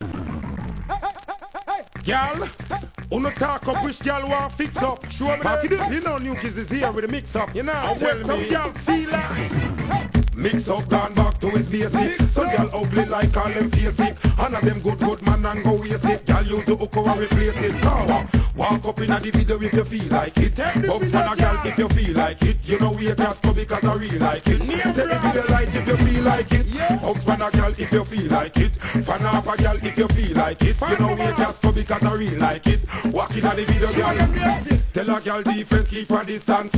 REGGAE
中毒性 & 疾走感とも抜群の好JUGGLIN!!